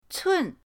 cun4.mp3